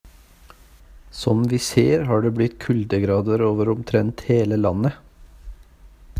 I have recorded my own voice saying the following in Norwegian: